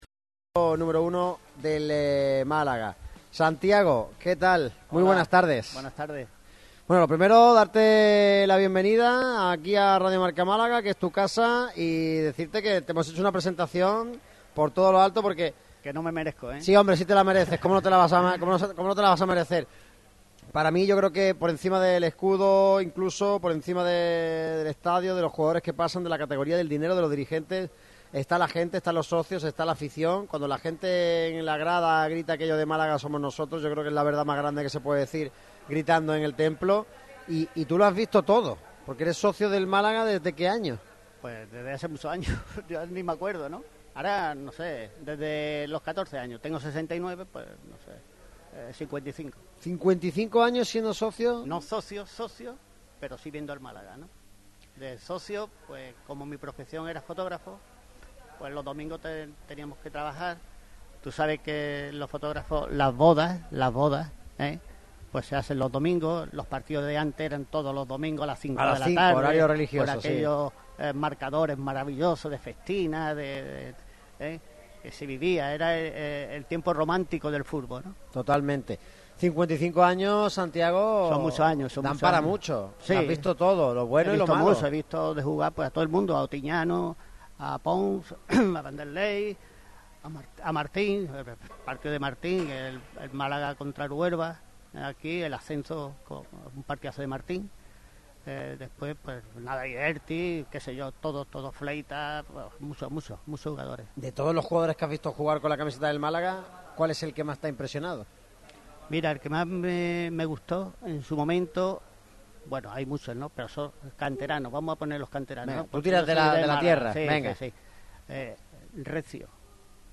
Radio MARCA Málaga ha tenido una charla